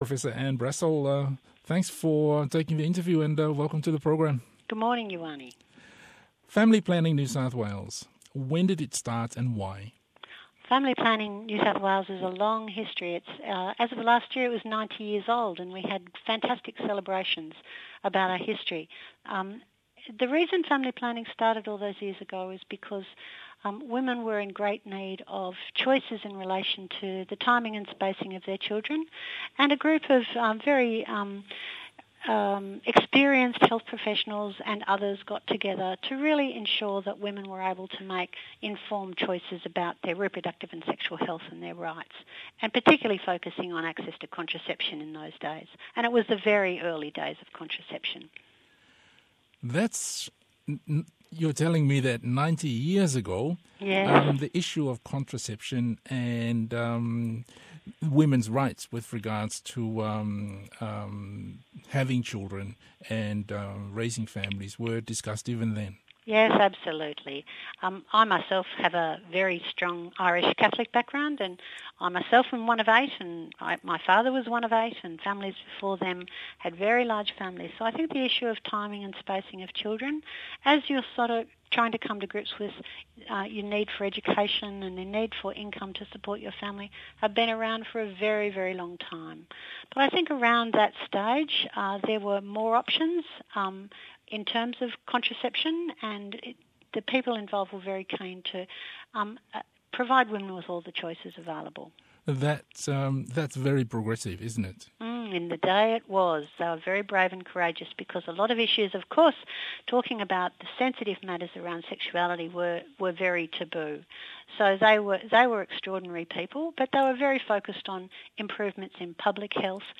talanoaga